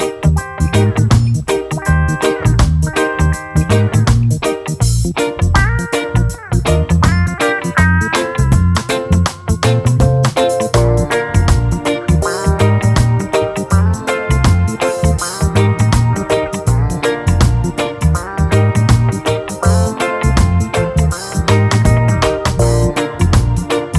no Backing Vocals Reggae 4:39 Buy £1.50